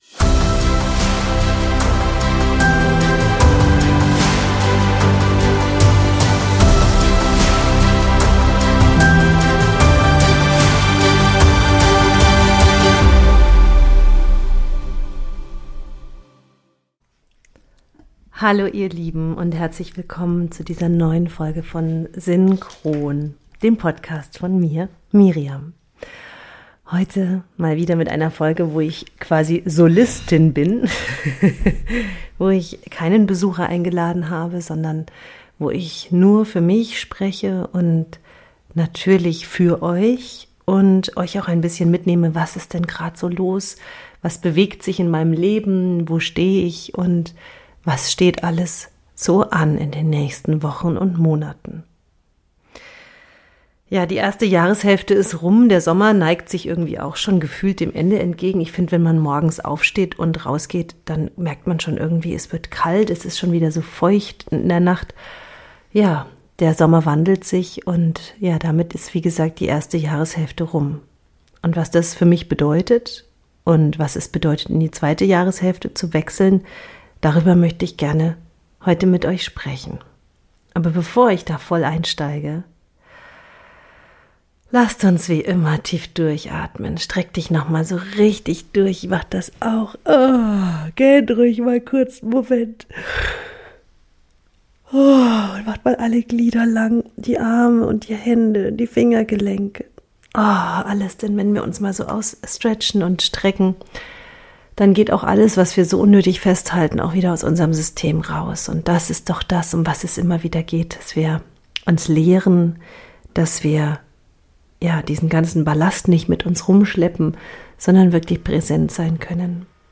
In dieser Solo-Folge nehme ich dich mit in mein inneres Erleben der letzten Wochen, ehrlich, persönlich und kraftvoll.